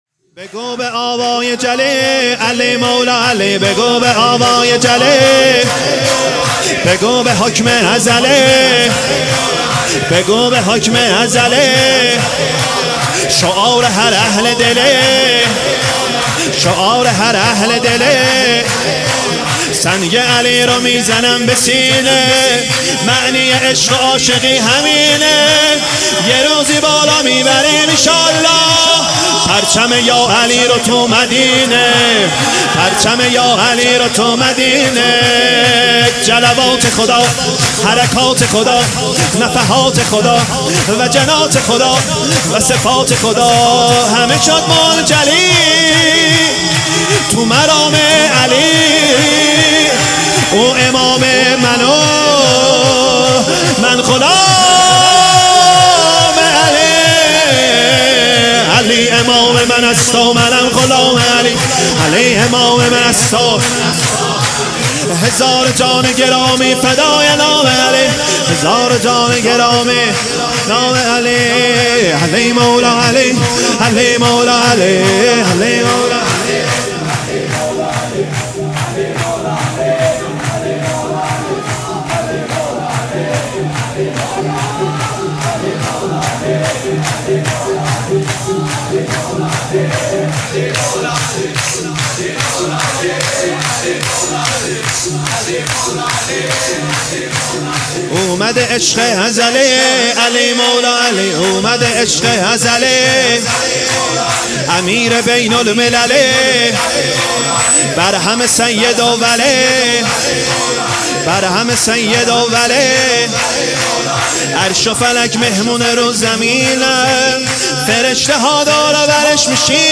سرود | علی مولا علی
ولادت امام علی(ع)